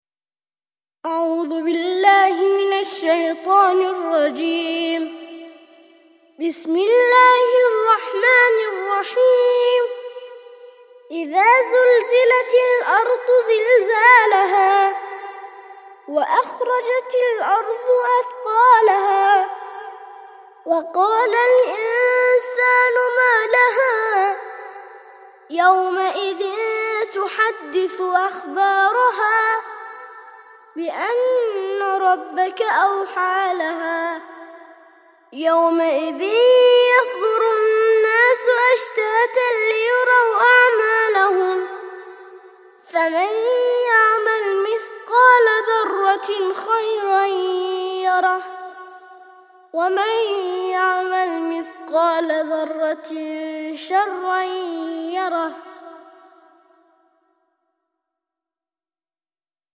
99- سورة الزلزلة - ترتيل سورة الزلزلة للأطفال لحفظ الملف في مجلد خاص اضغط بالزر الأيمن هنا ثم اختر (حفظ الهدف باسم - Save Target As) واختر المكان المناسب